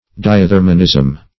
Search Result for " diathermanism" : The Collaborative International Dictionary of English v.0.48: Diathermanism \Di`a*ther"ma*nism\, n. The doctrine or the phenomena of the transmission of radiant heat.